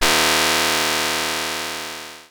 Alert08.wav